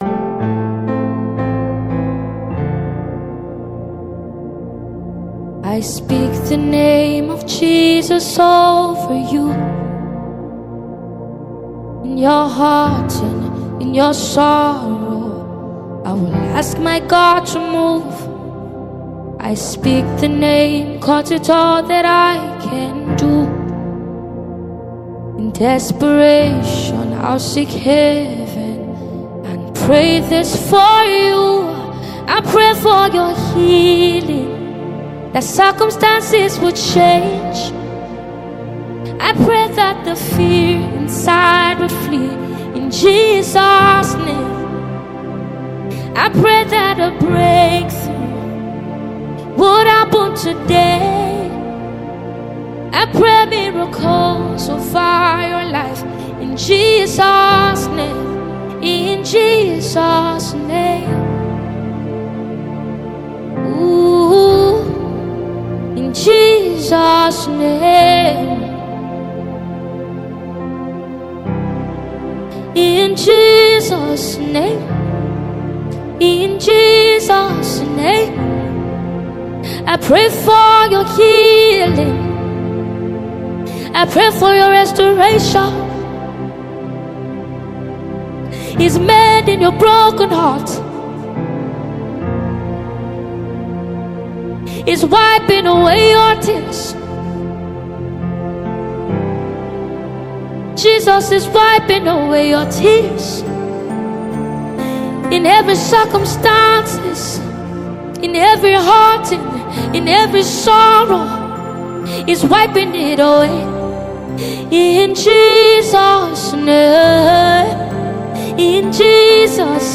Contemporary Christian music singer